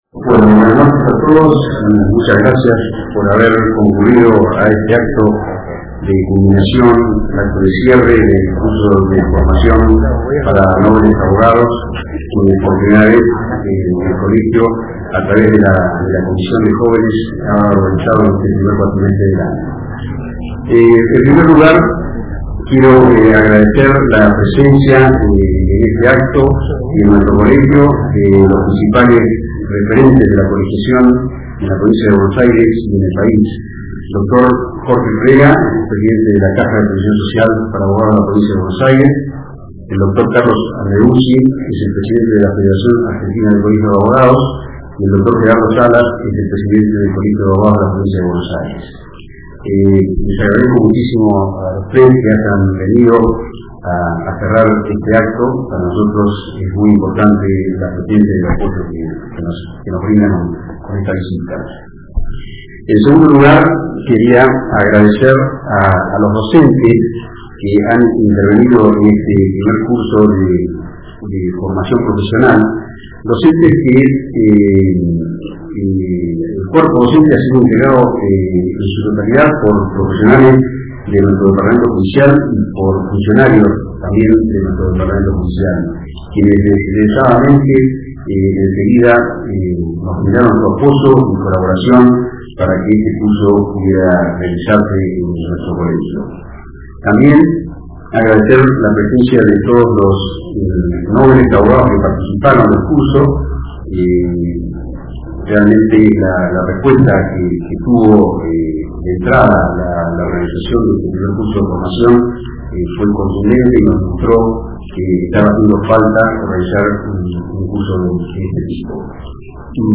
Acto de Cierre -13 de julio de 2011.